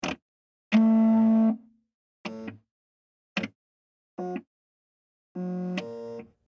Scanner; Warming Up And Moving Scan Light, Variety Of Clicks And Buzzes.